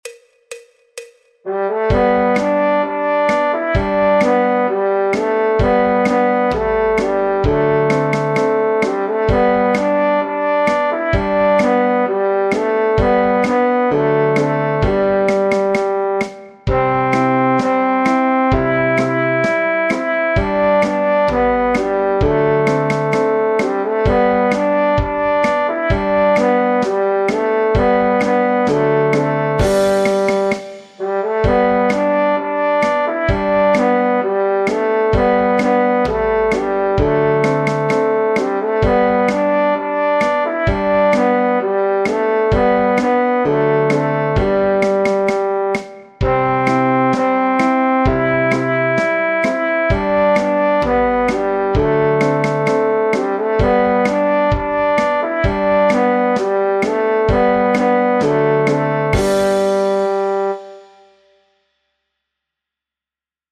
Trombón / Bombardino
Folk, Popular/Tradicional